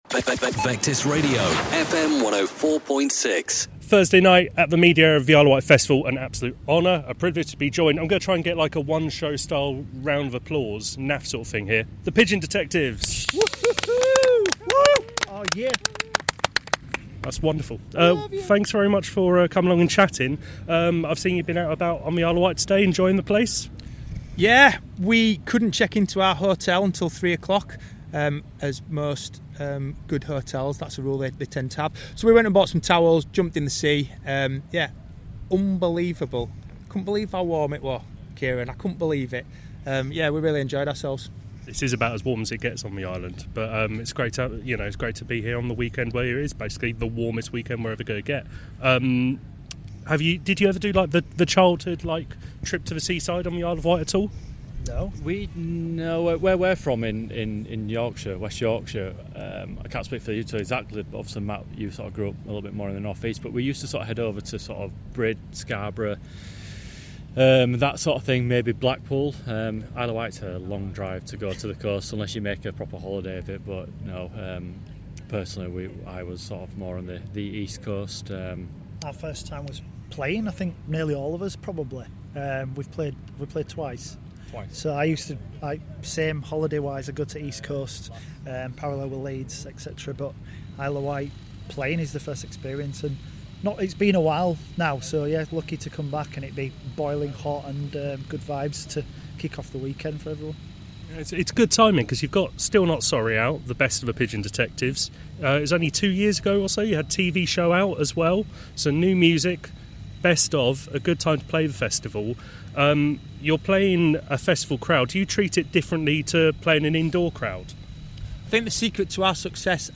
Isle of Wight Festival 2025 - The Pigeon Detectives on buying towels and jumping into the sea, and their Thursday night 'Best of...' set